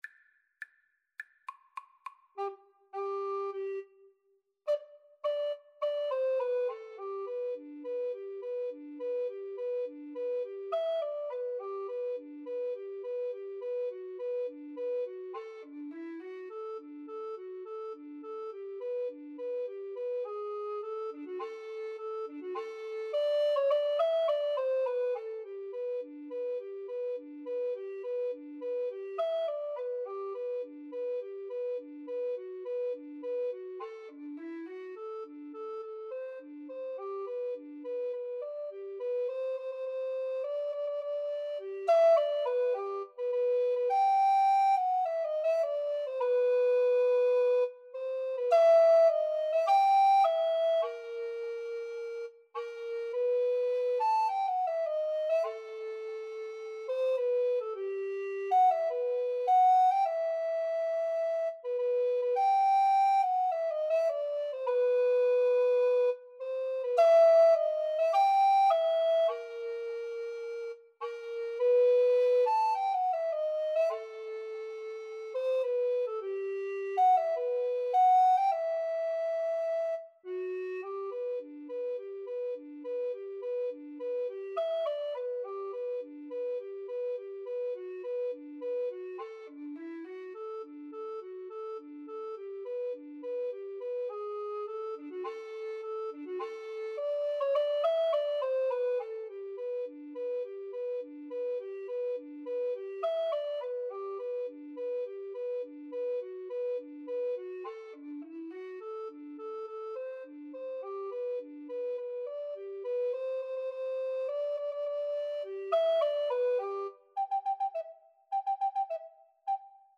Alto RecorderTenor Recorder
Quick March = c.104
Classical (View more Classical Recorder Duet Music)